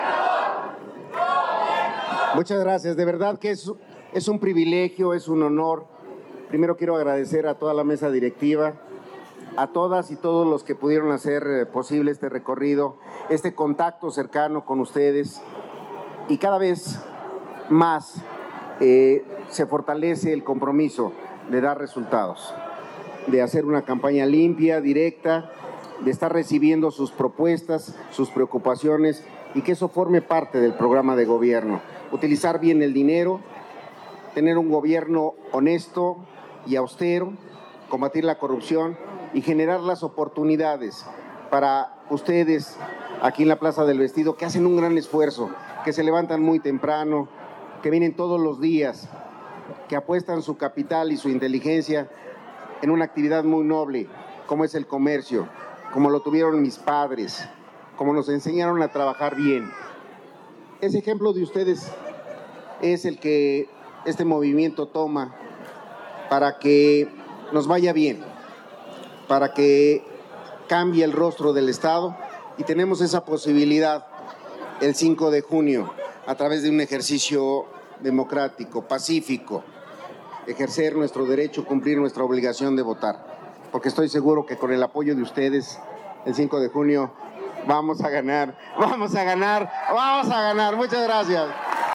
Ante comerciantes, Menchaca se comprometió a “utilizar bien el dinero, tener un gobierno honesto y austero, y generar las oportunidades para ustedes aquí en la Plaza del Vestido, que hacen un gran esfuerzo, que se levantan muy temprano y que vienen todos los días, que apuestan su capital y su inteligencia en una actividad muy noble como es el comercio”, aseguró.
Julio-Menchaca-Plaza-del-vestido.mp3